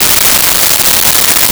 Crickets Loop
Crickets Loop.wav